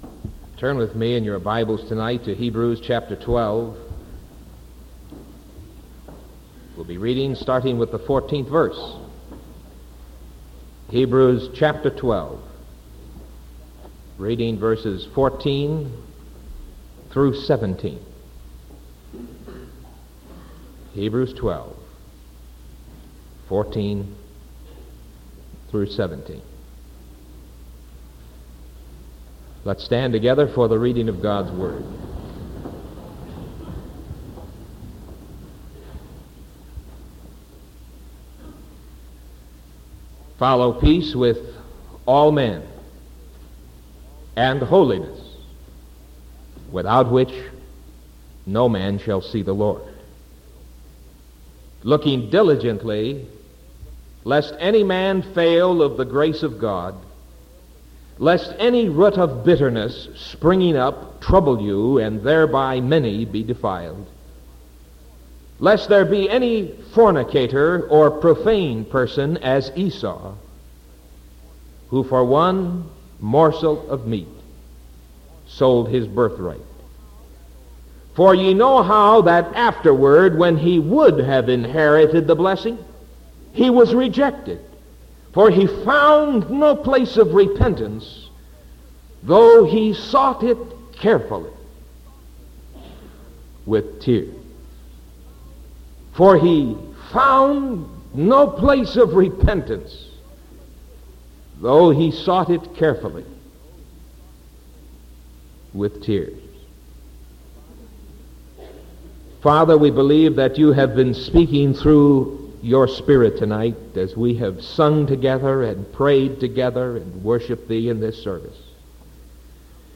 Sermon from November 10th 1974 PM